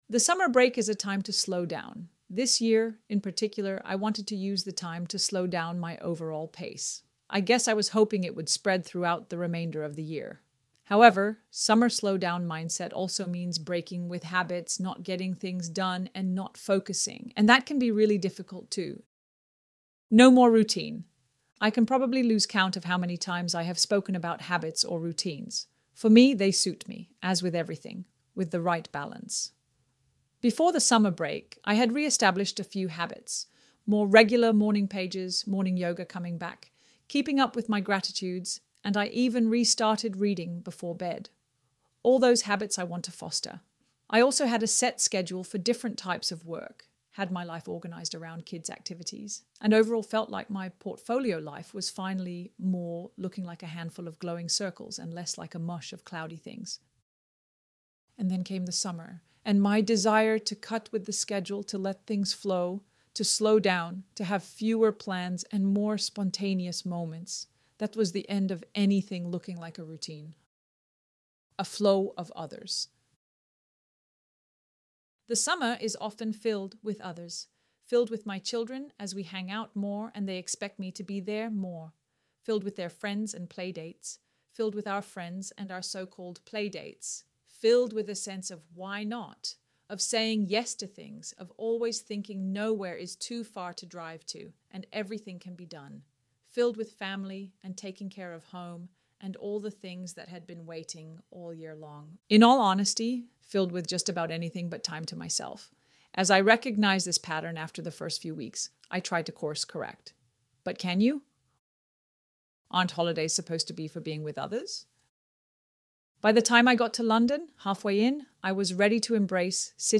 ElevenLabs_The_summer_break_is_a_time.mp3